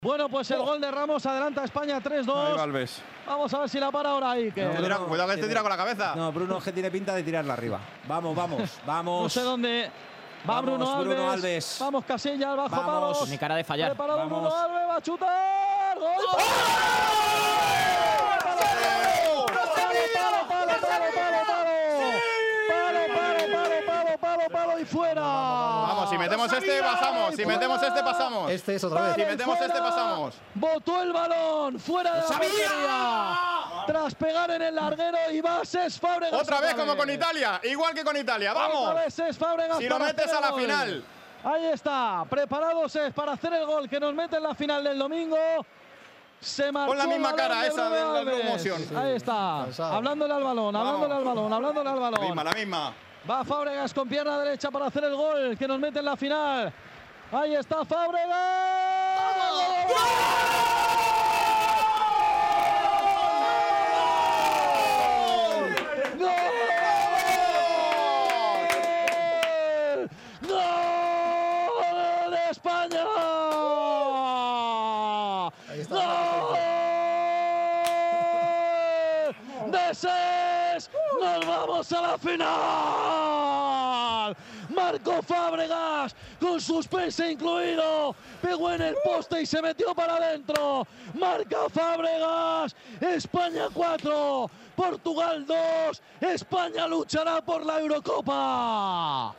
narró los penaltis